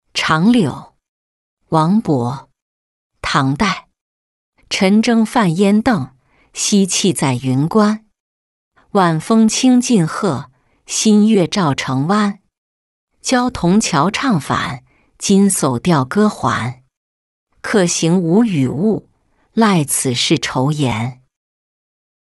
春日忆李白-音频朗读